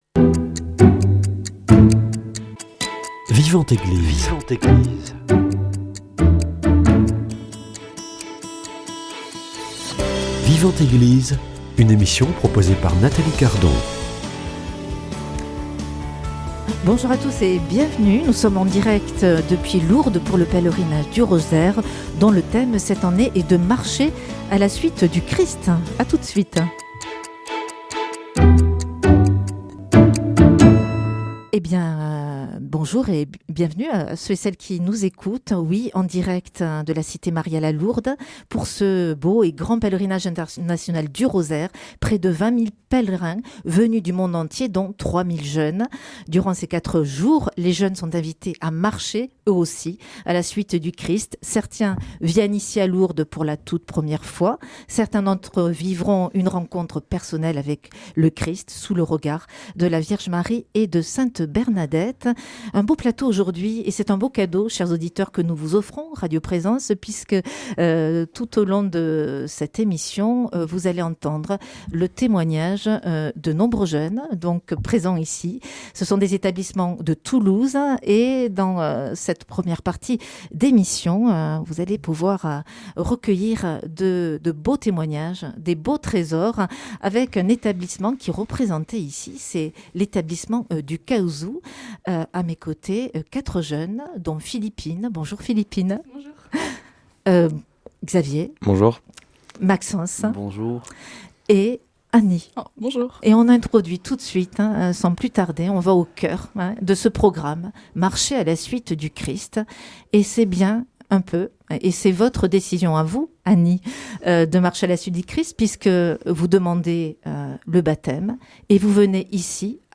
Invités en plateau